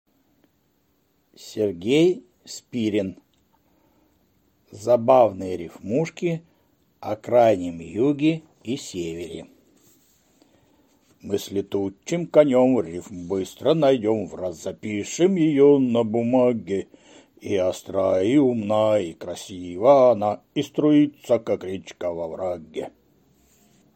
Аудиокнига Забавные рифмушки о Крайнем Юге и Севере | Библиотека аудиокниг